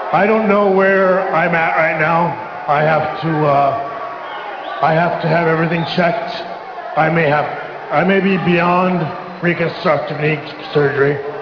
Back to Shawn, who was giving us this long, rambling, and
quite possibly drunken soliloquoy about how he was injured and how a doctor had told him he could never wrestle again.